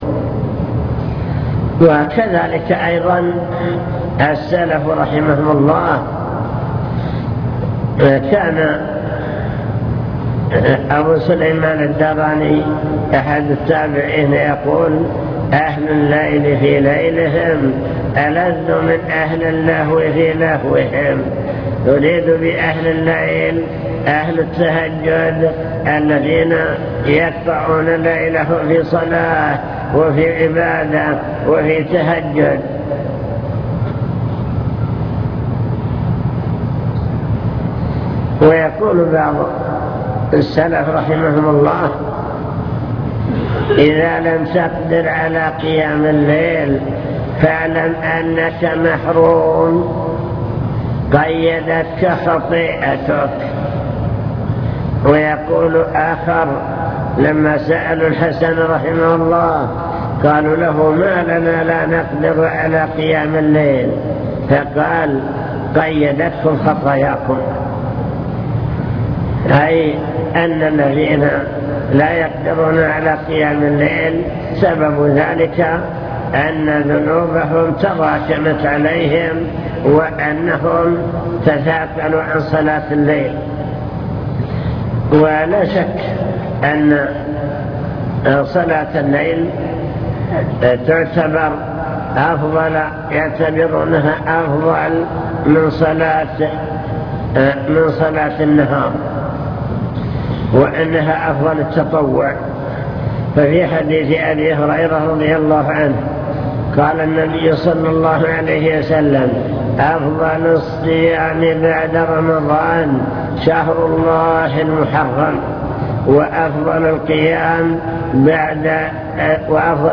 المكتبة الصوتية  تسجيلات - محاضرات ودروس  محاضرة بعنوان قيام الليل